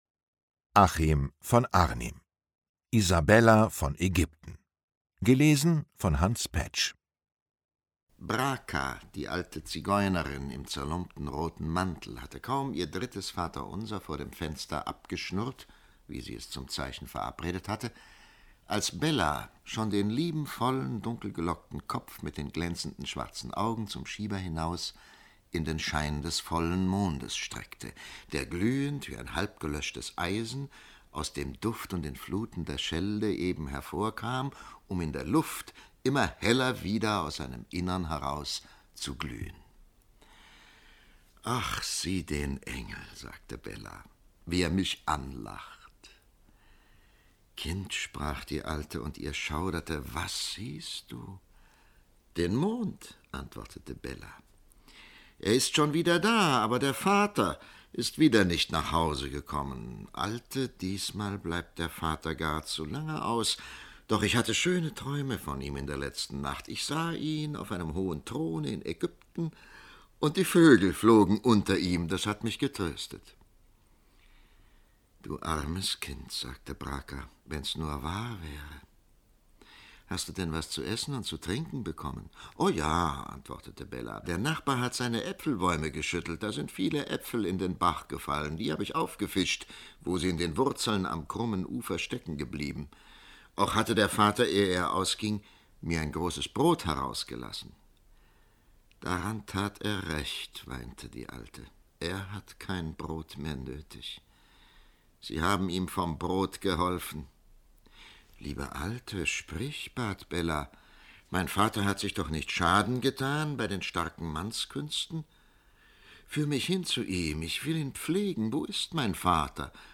Lesung mit Hans Paetsch (1 mp3-CD)
Hans Paetsch (Sprecher)